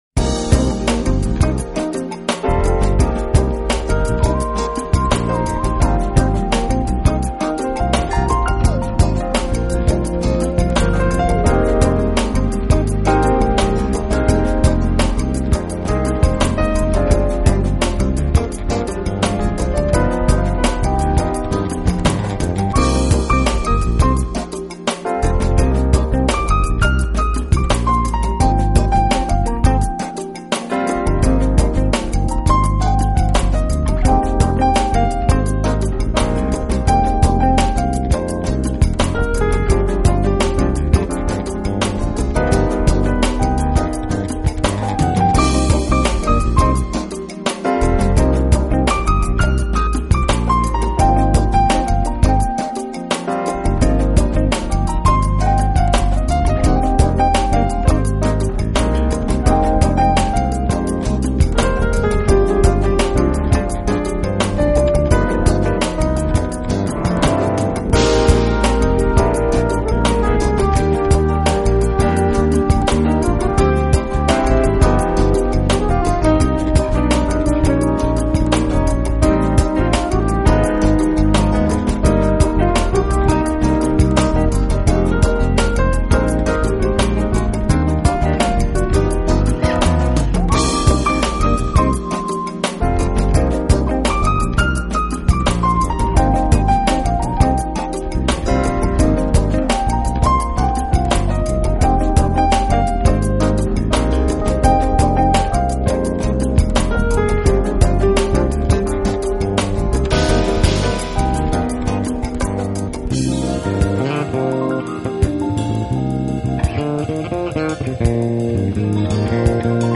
音乐类型:  Smooth Jazz/Funk